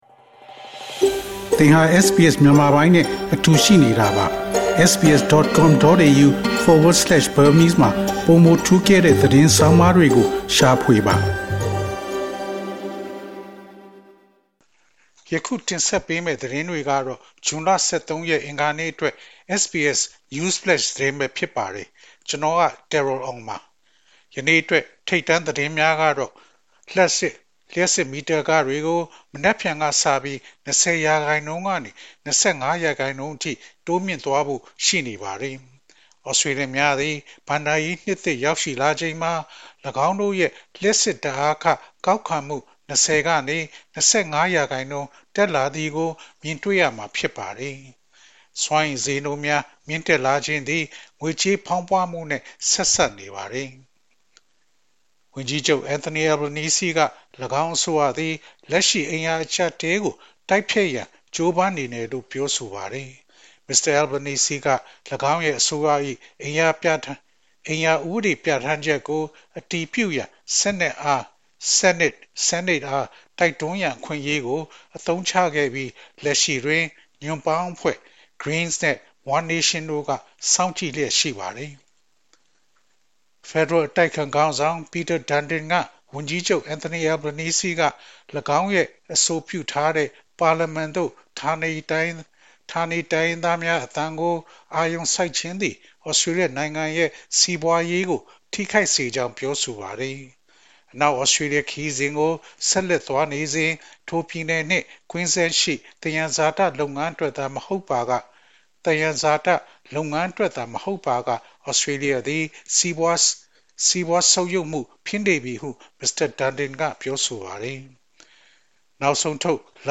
Burmese News Flash